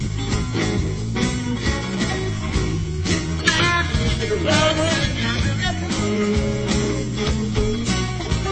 blues_blues.00008.mp3